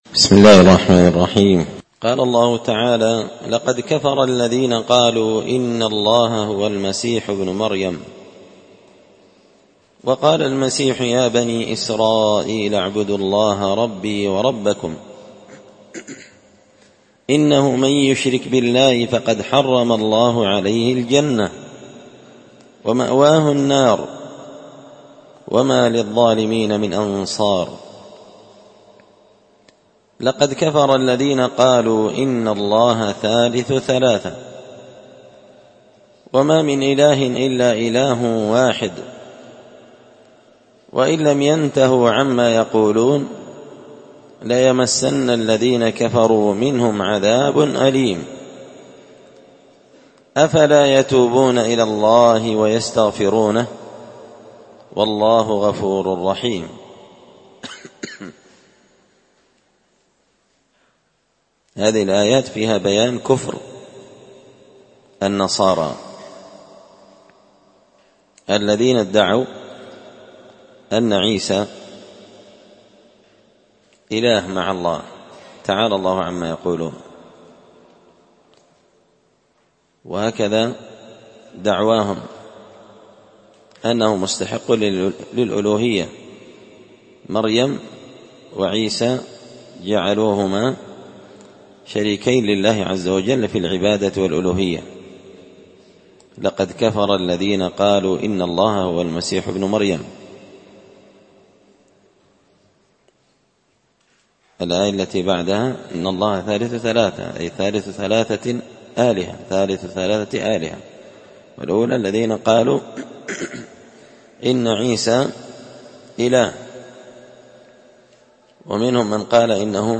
ألقيت هذه الدروس في 📓 # دار _الحديث_ السلفية _بقشن_ بالمهرة_ اليمن 🔴مسجد الفرقان